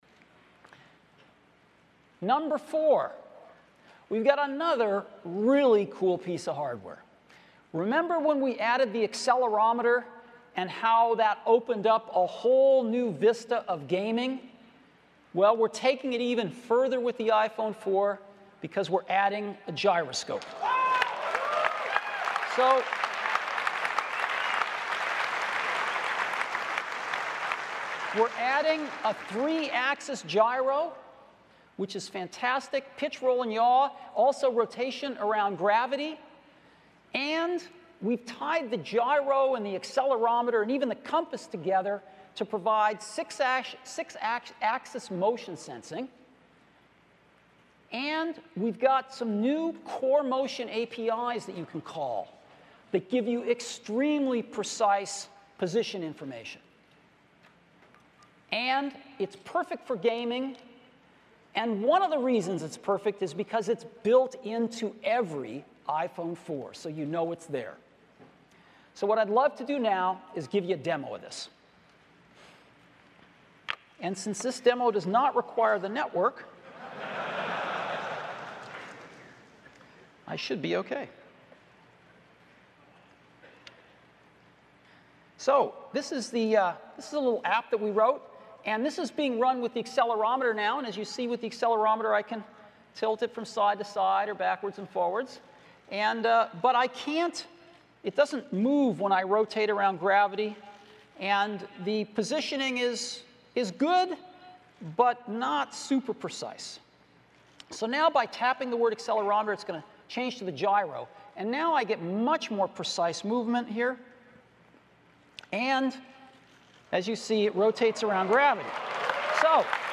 2010年苹果全球开发者大会 乔布斯介绍初代iPhone 4(6)